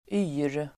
Uttal: [y:r]